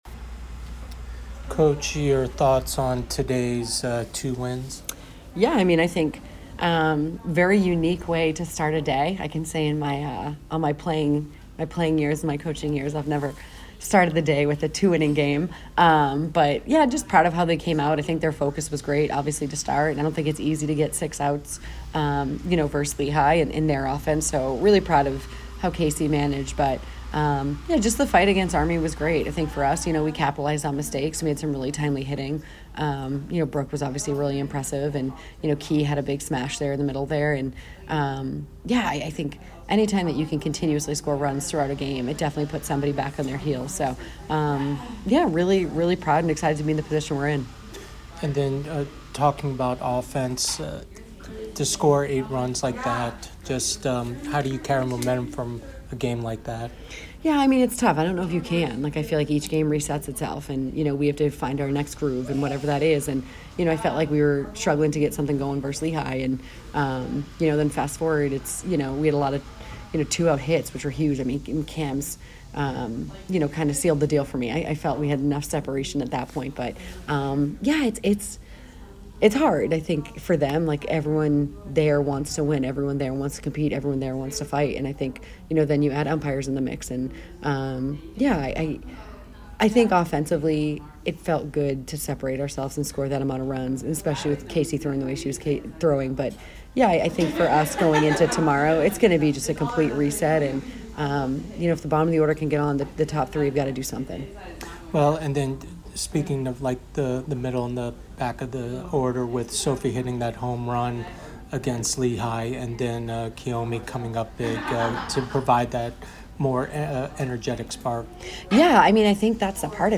Softball / PL Tournament Day 2 Interview